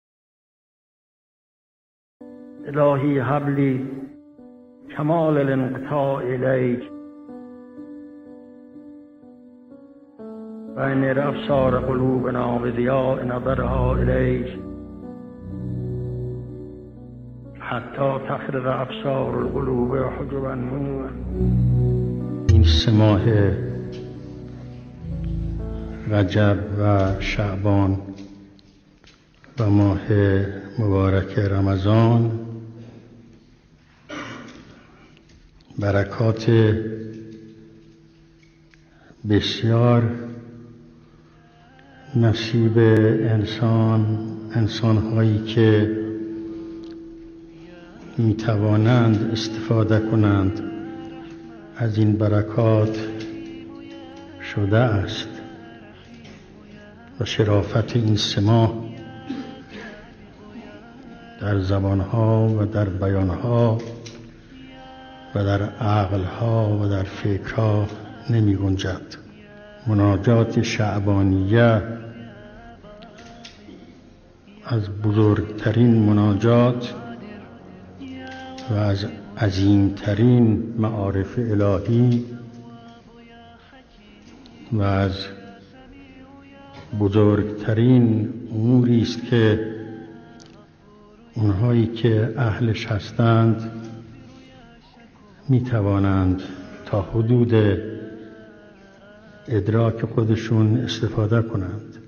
گروه چندرسانه‌ای ــ فرازی از مناجات شعبانیه و بیاناتی از برکات و شرافت ماه شعبان را با بیان امام خمینی(ره) می‌شنوید.